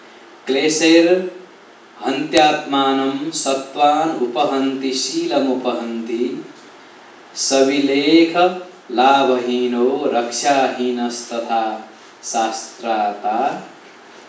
āryā